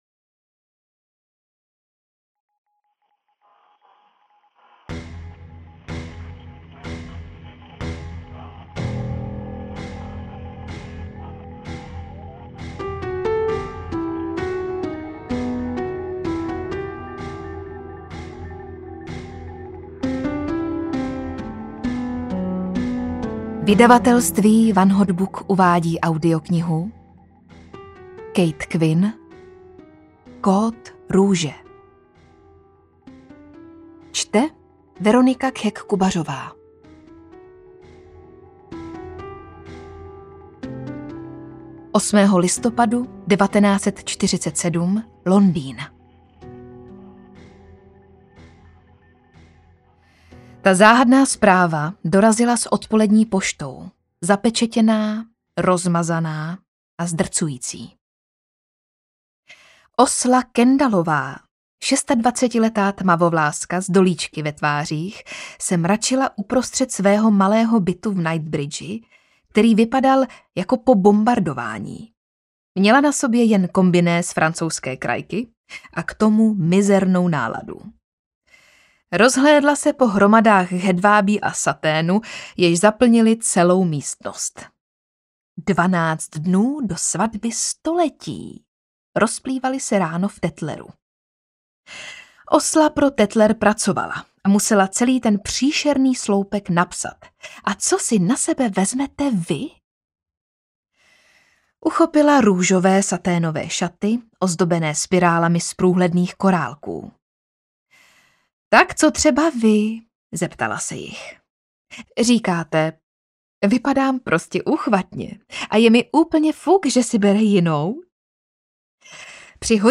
Kód růže audiokniha
Ukázka z knihy
• InterpretVeronika Khek Kubařová